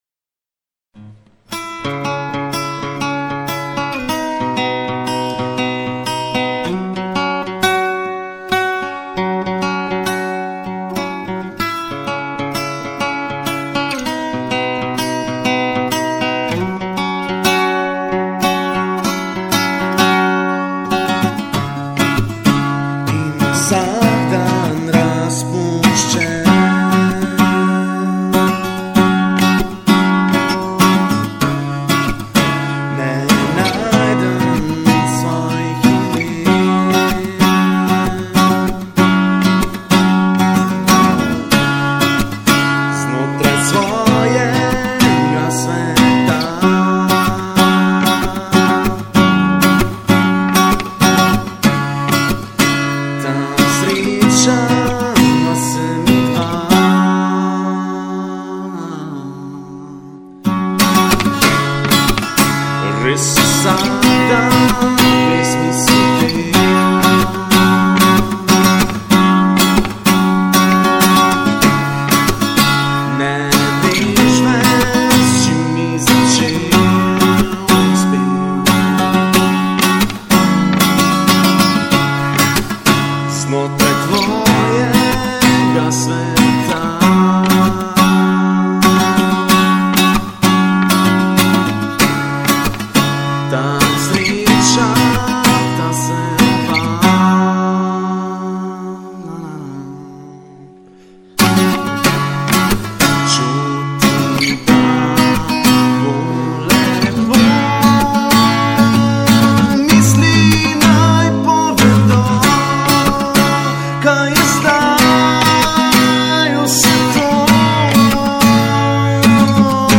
Delno zato, ker tvoj song vsebuje samo kitaro in vokal, delno pa tudi zato ker ni obdelan.
The secret: komad je sicer napicen, a zveni grobo - verjetno samo hitra sala - najbrz si ne zelis tega. Torej - v tem zvocnem primeru je vokal pod nivojem kitare - in tudi ko dvignes glasnost bo se vedno pretih in ne bo zvenelo jasno - razlika je obcutna - ne gre za dlakocepljejne in okus - torej - najprej popedenaj razmerje. Ker ko spravis vokal na nek nivo je kitara ze hudo glasna in groba...